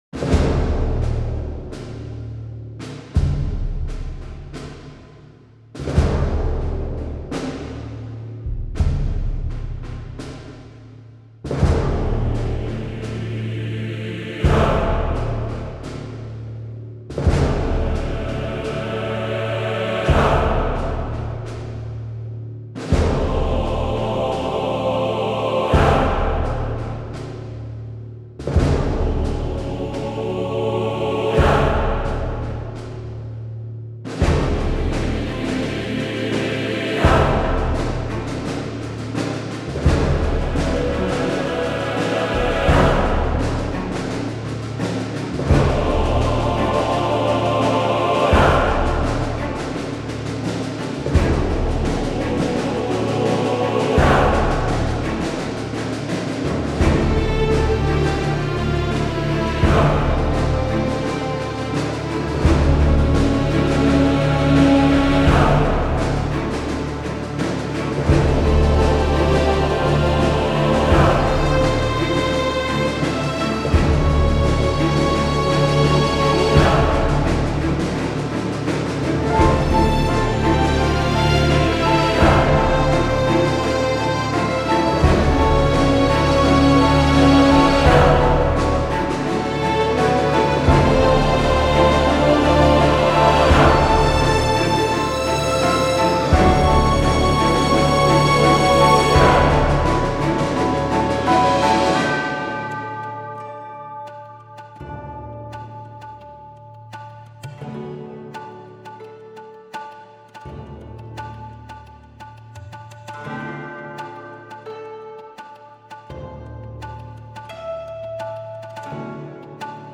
Genre: Talk Show
• Street Interviews: Raw, on-the-spot conversations with people sharing their experiences and perspectives on social change, housing, and resilience.